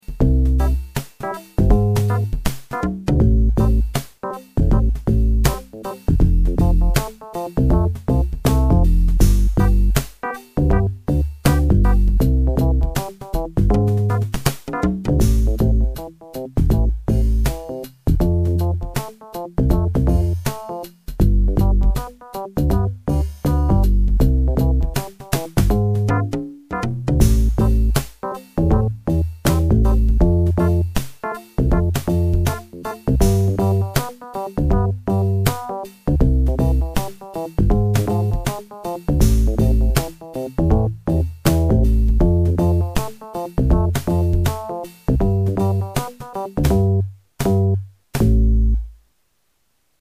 The Kid You Need Karaoke.mp3